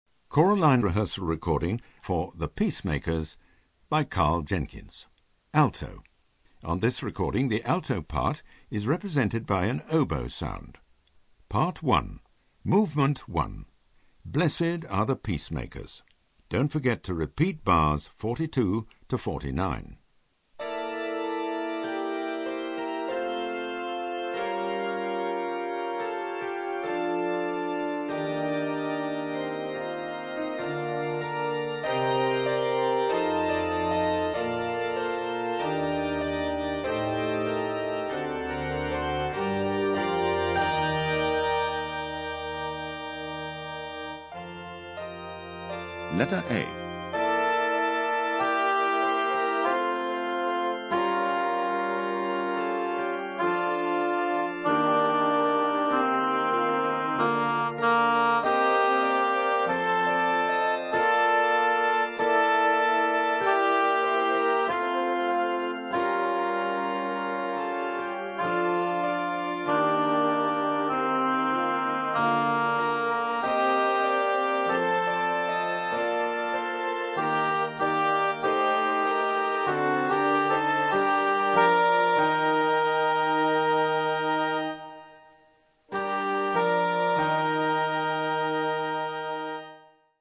Alto
Easy To Use narrator calls out when to sing
Don't Get Lost narrator calls out bar numbers
Vocal Entry pitch cue for when you come in